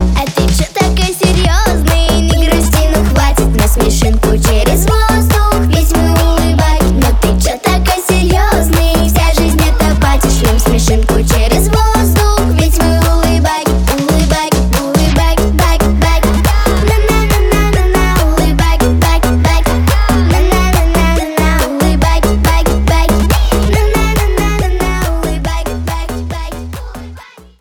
веселые